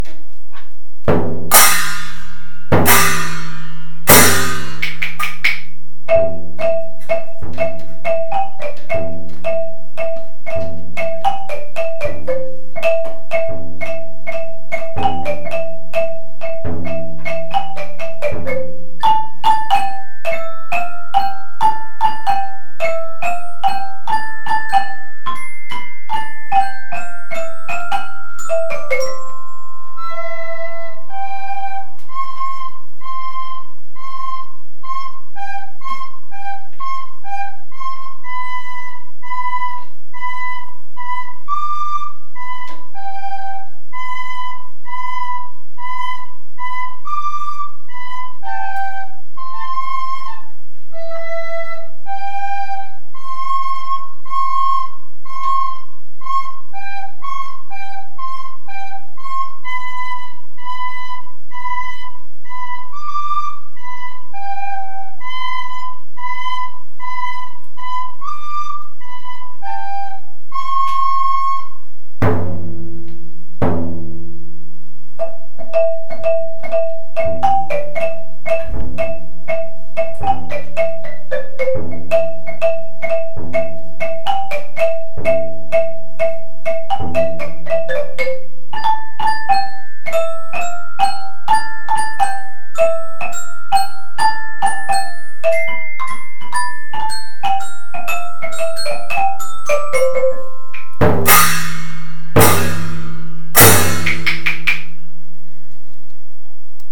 Per aquest motiu hem fet una versió amb els instruments que tenim a l’escola d’un dels fragments de la famosa obra.